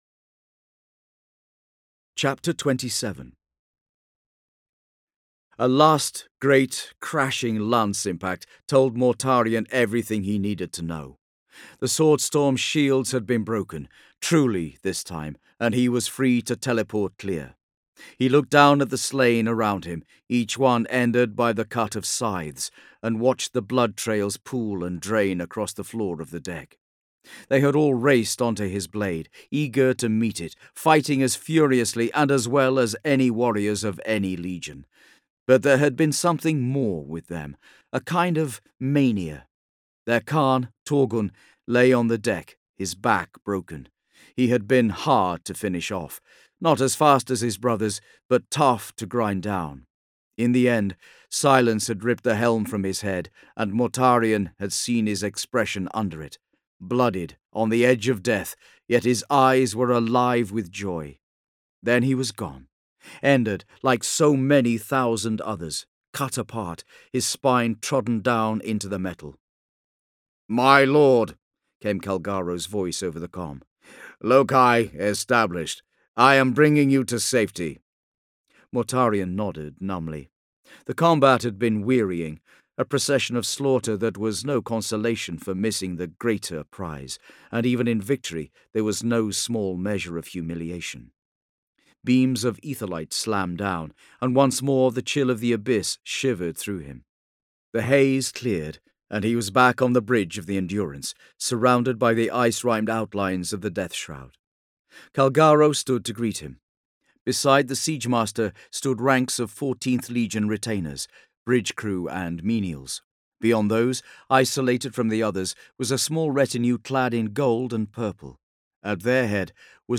Games/MothTrove/Black Library/Horus Heresy/Audiobooks/The Complete Main Series/HH 31-40/36